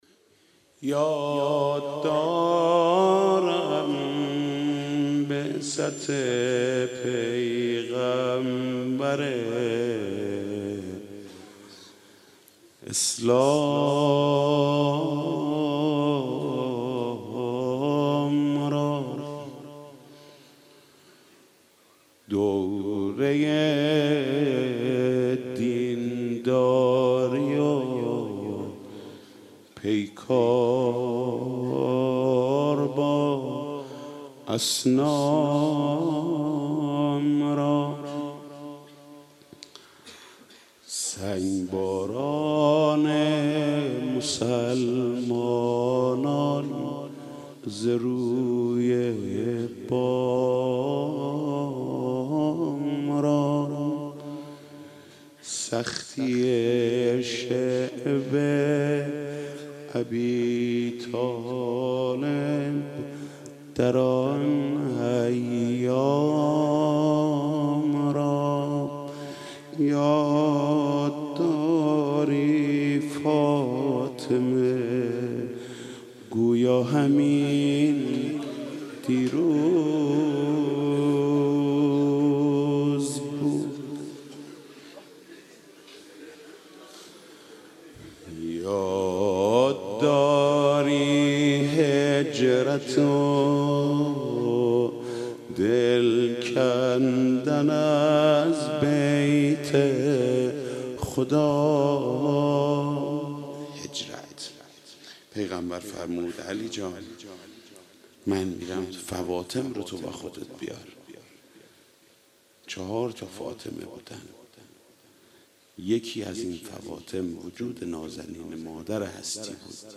دانلود مداحی اشک من و غم ماتم تو - دانلود ریمیکس و آهنگ جدید
مداحی فاطمیه
روضه خوانی